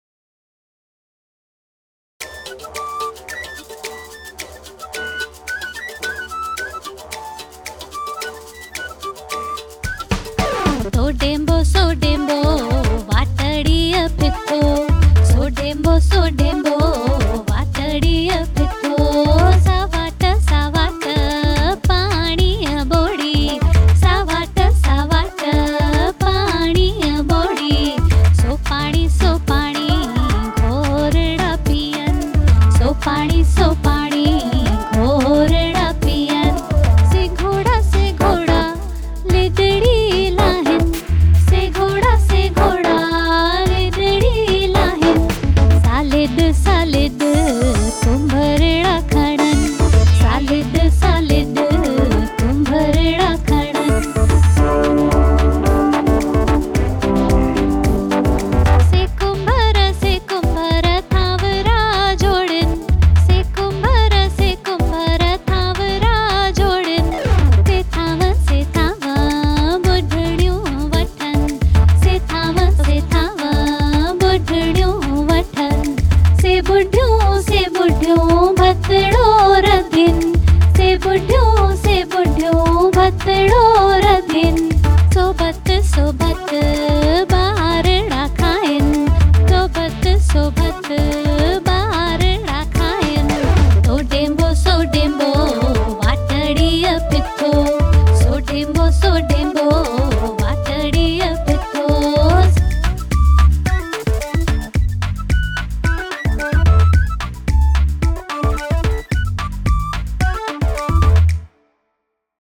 Sindhi Nursary Rhymes